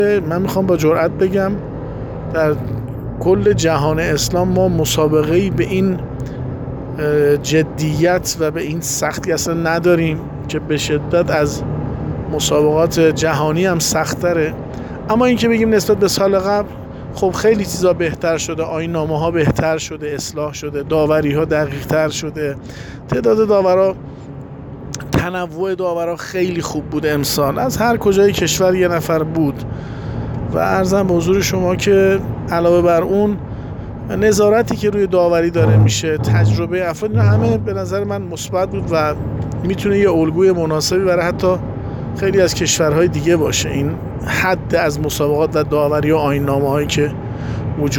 در گفت‌و‌گو با خبرنگار ایکنا